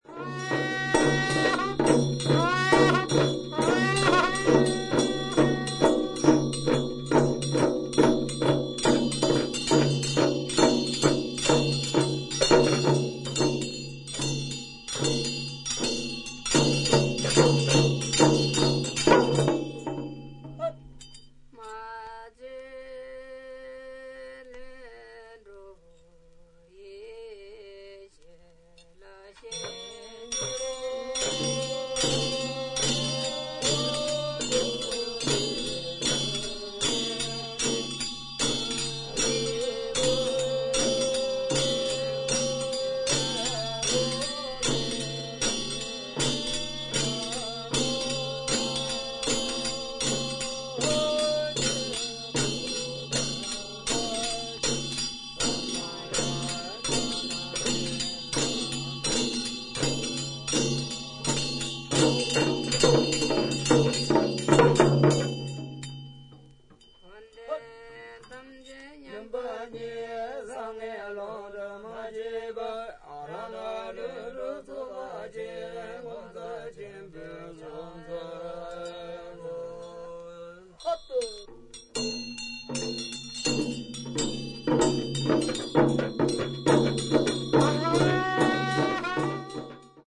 インド北部/ヒマーチャル・プラデーシュ州にて録音。肺結核を病んでいる一人のチベット人の為に、一日中行われた悪霊払いの儀式を生々しくレコーディングした作品です。